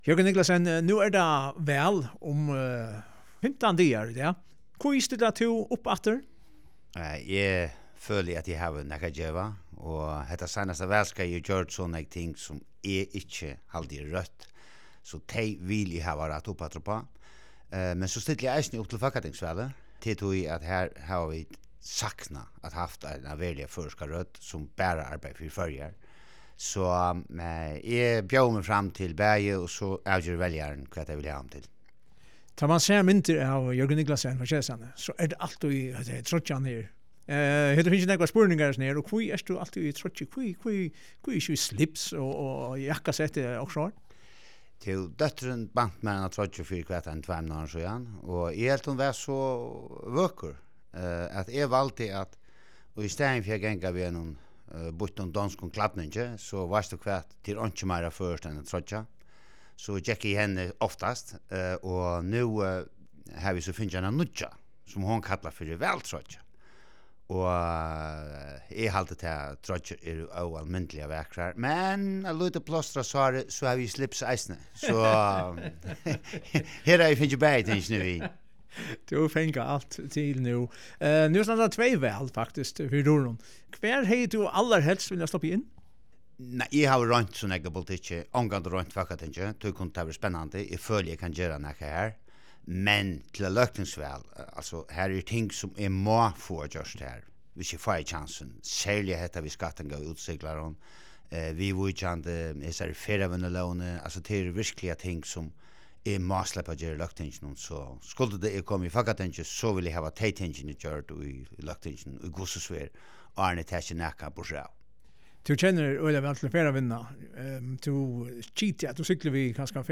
Í hesum práti fær Jørgen Niclasen nakrar øðrvísi spurningar.